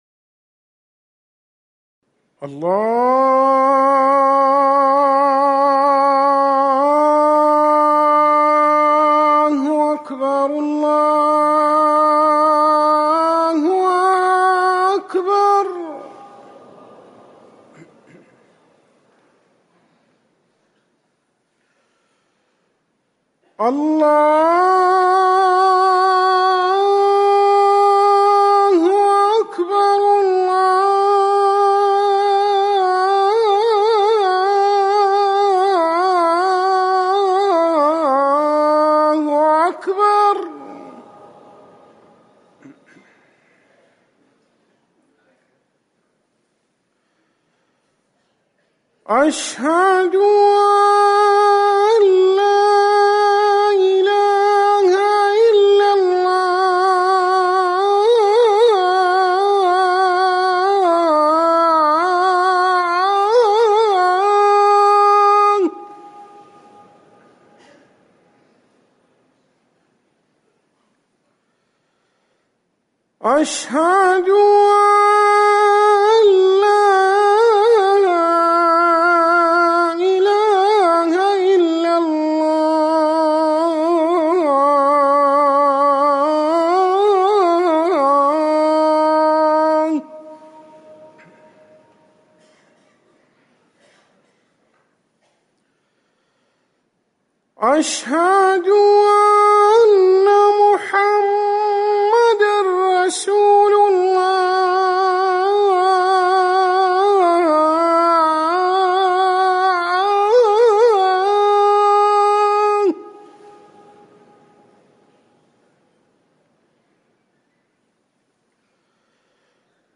أذان الفجر الثاني
تاريخ النشر ١٥ صفر ١٤٤١ هـ المكان: المسجد النبوي الشيخ